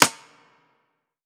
ATR Snare (19).wav